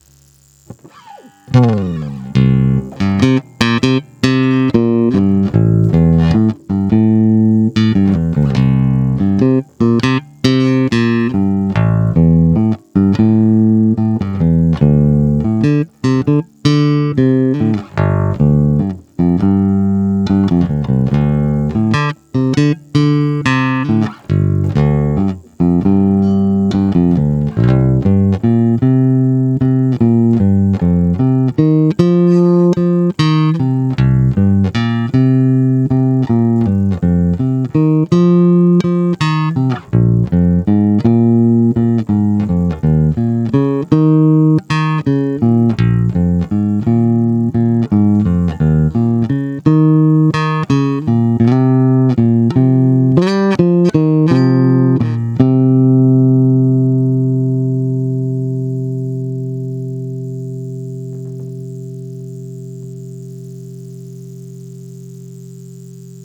Nahrávky s původními snímači a obyčejnými strunami Olympia:
Původní Olympia oba na plno